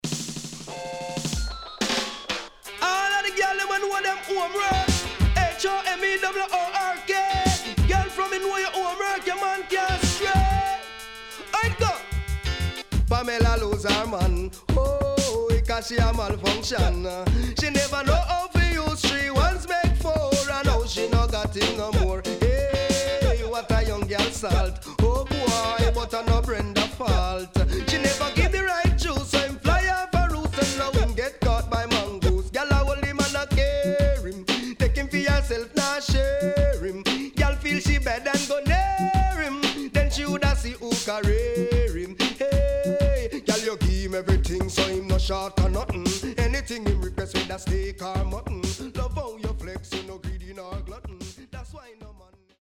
HOME > 90's〜